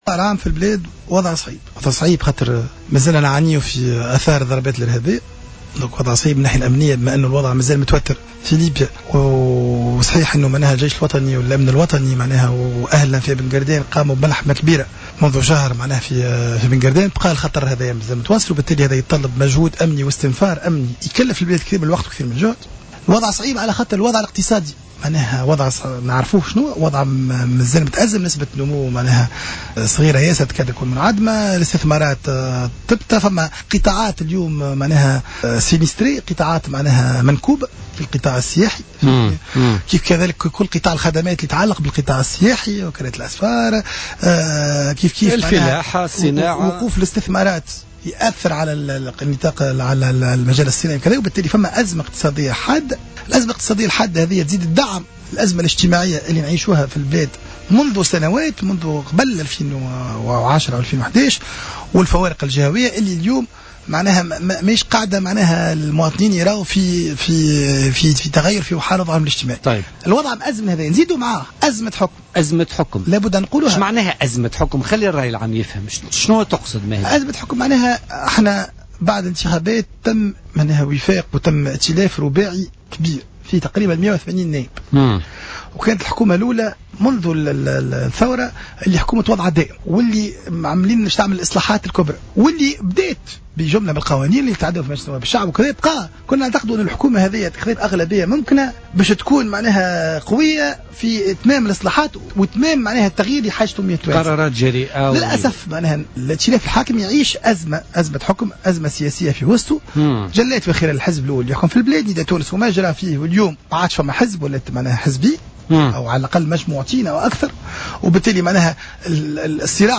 وأضاف بن غربية ضيف برنامج "بوليتيكا" اليوم الأربعاء إن الأزمة تتجلى خاصة في الخلافات التي عصفت بعدد من الأحزاب الحاكمة وأثرت على آدائها وكذلك في آداء بعض الأطراف في المعارضة التي تلقي أسباب الفشل على الحكومة في حين أن الأسباب أعمق من ذلك بكثير، وفق تعبيره.